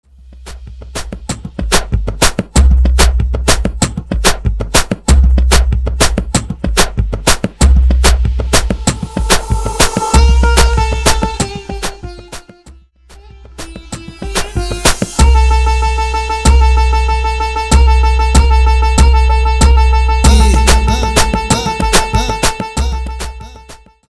Intro Dirty